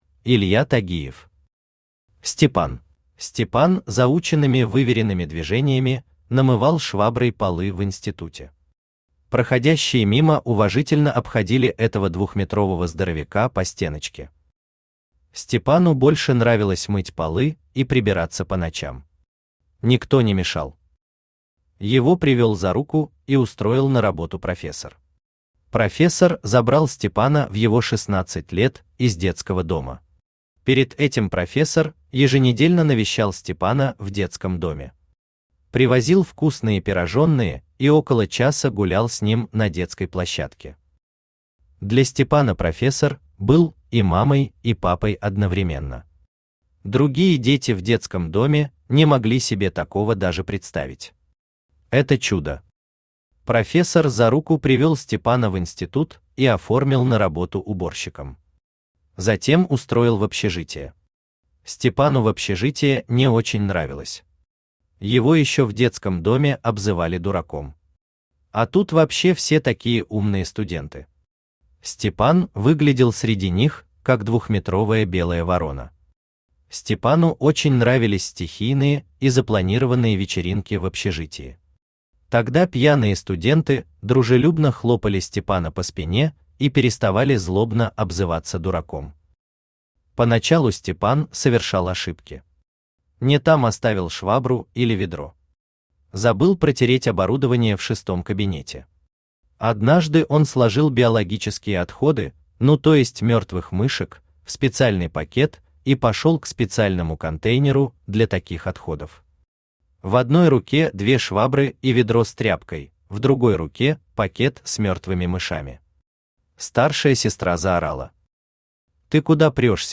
Аудиокнига Степан | Библиотека аудиокниг
Aудиокнига Степан Автор Илья Михайлович Тагиев Читает аудиокнигу Авточтец ЛитРес. Прослушать и бесплатно скачать фрагмент аудиокниги